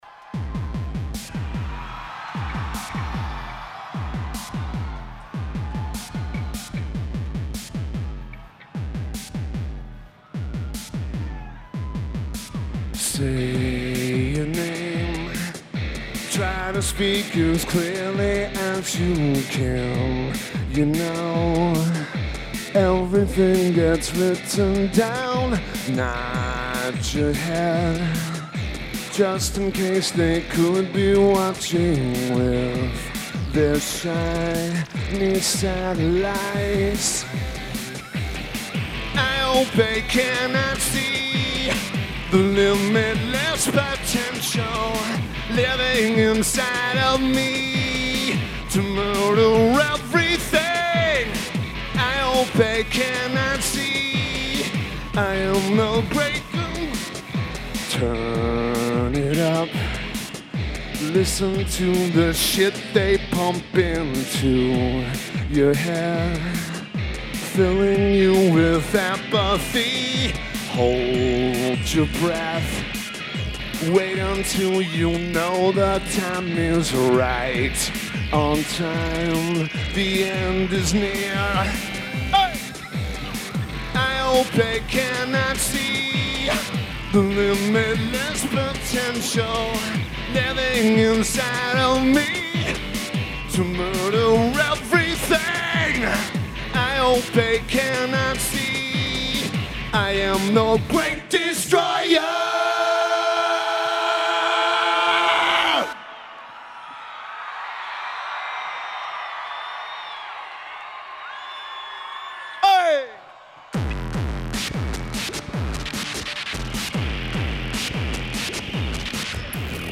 Sommet Center